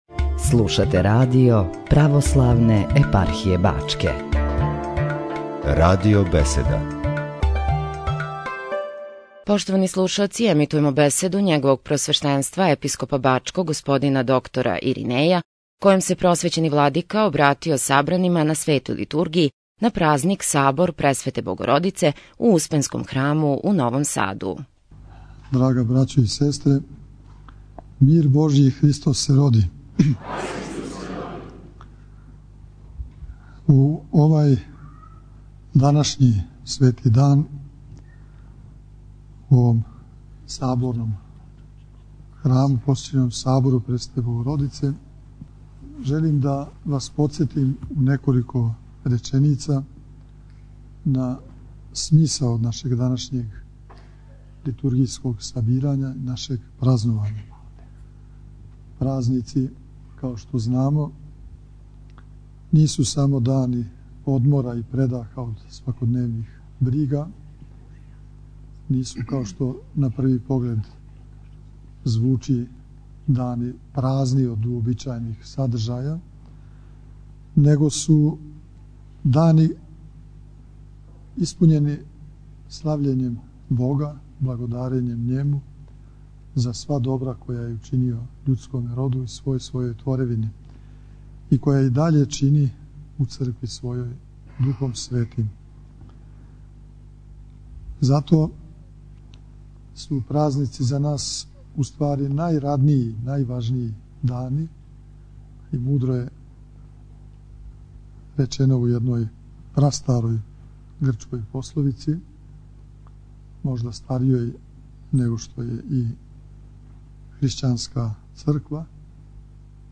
Сабор Пресвете Богородице у Светоуспенској цркви у Новом Саду
Владика Иринеј је упутио Божићни поздрав присутном сабрању, објаснио смисао Празника и службу Пресвете Богородице у светајни спасења.